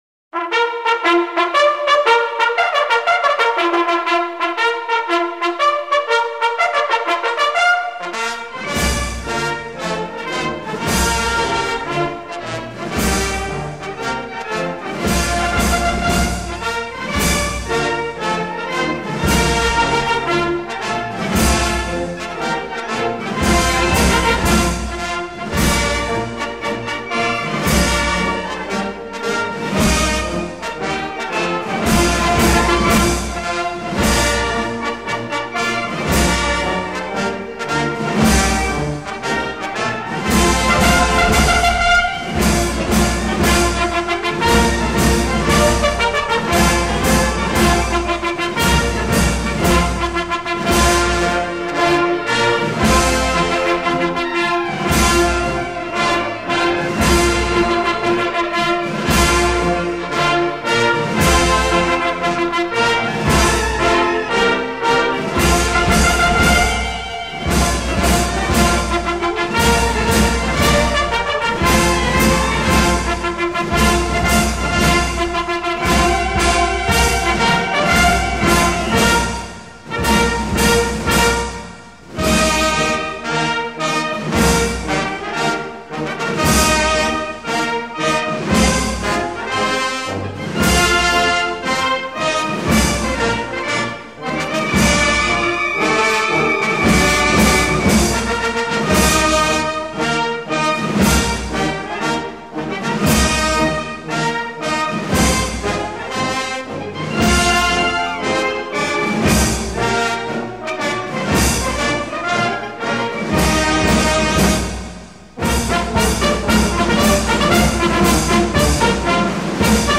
99 R Marche de la 2e DB V. CLOWEZ Défilé 🖼